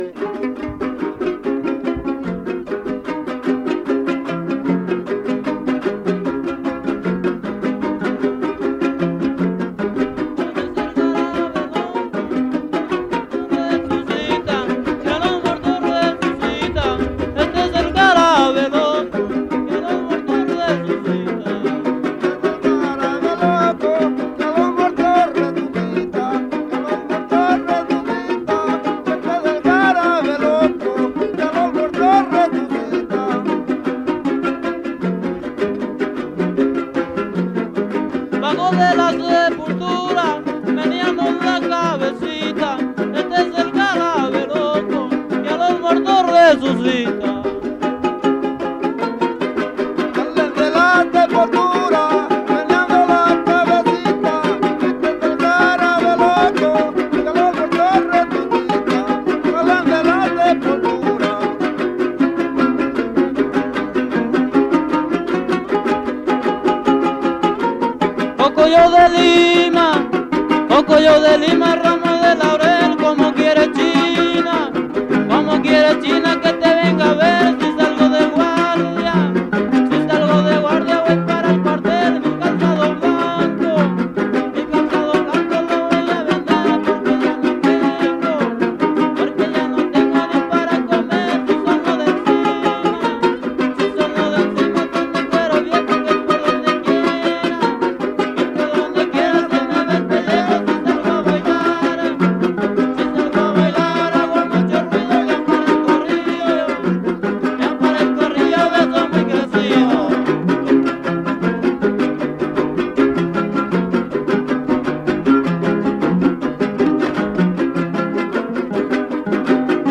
• Amatitlán y los pinos (Grupo musical)
Cuarto Encuentro de jaraneros